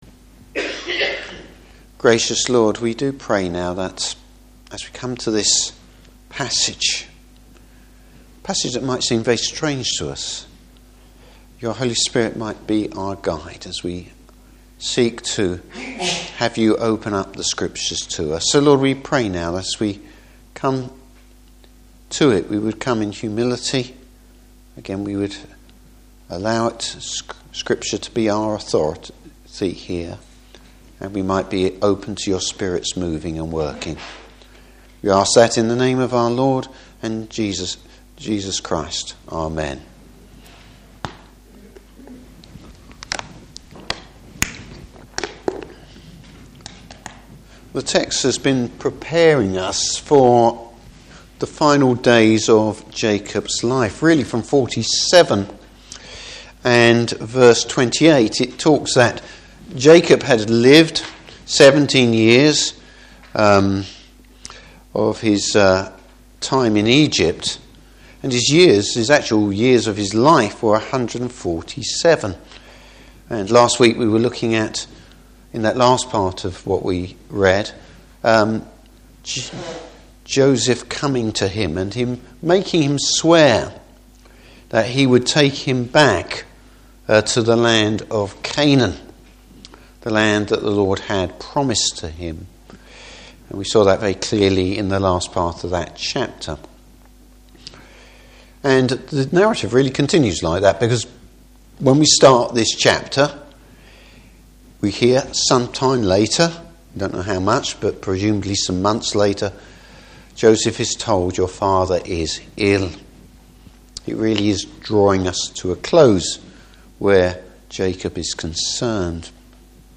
Service Type: Evening Service A case of unexpected blessing, Topics: Grace.